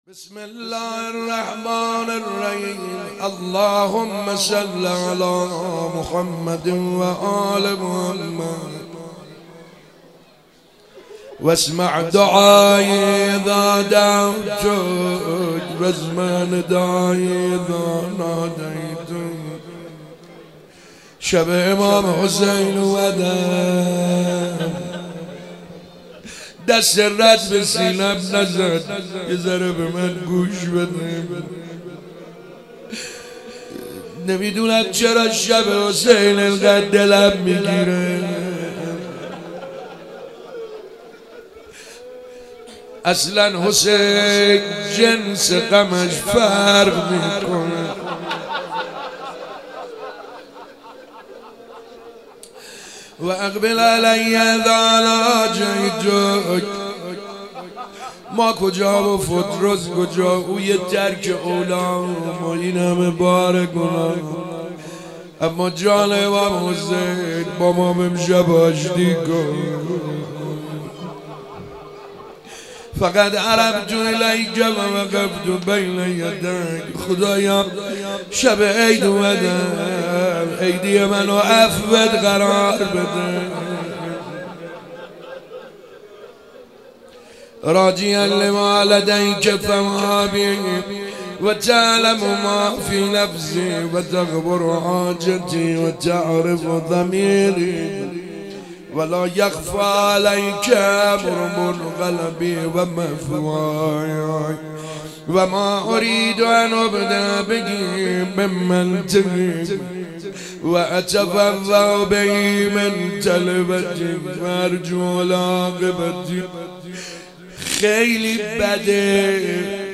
شب ولادت امام حسین (ع) - شعبان 98